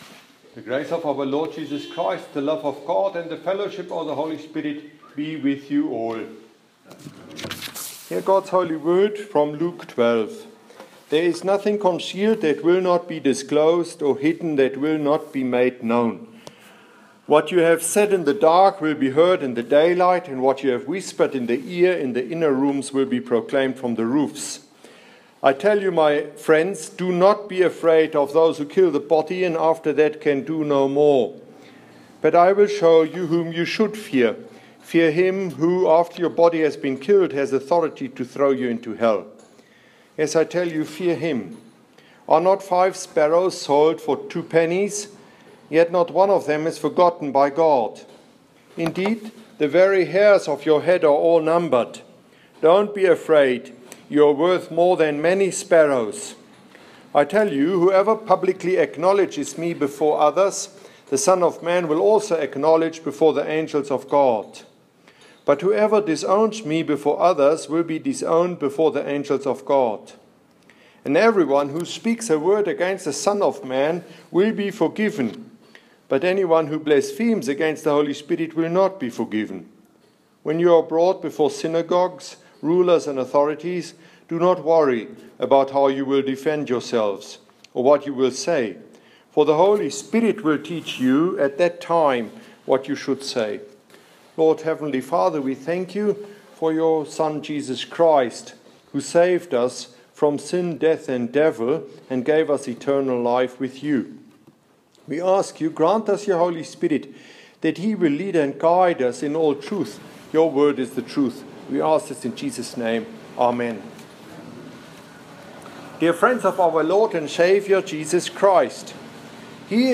Here's this mornings sermon held at Matins on the gospel of St.Luke 12:2-12 to read: Lk12,2-12 Matins 2016.2 and to listen to: